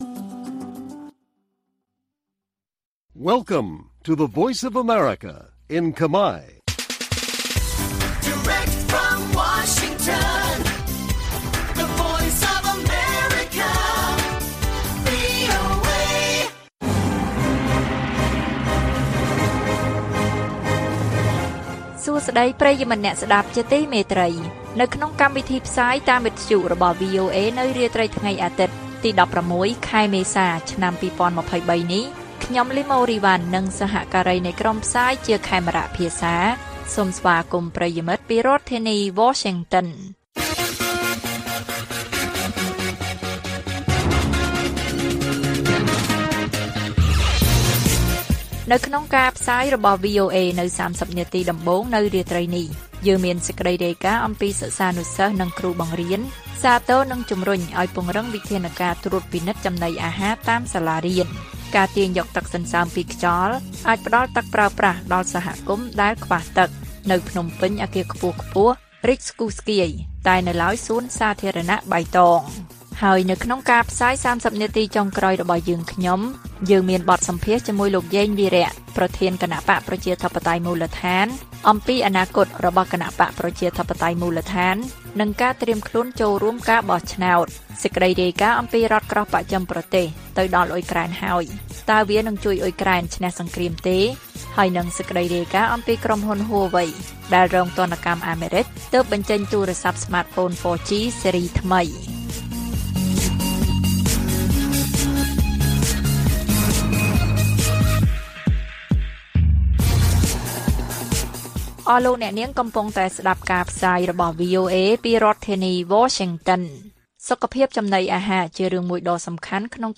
ព័ត៌មានពេលរាត្រី ១៦ មេសា៖ សិស្សានុសិស្សនិងគ្រូបង្រៀនសាទរនិងជំរុញឱ្យពង្រឹងវិធានការត្រួតពិនិត្យចំណីអាហារតាមសាលារៀន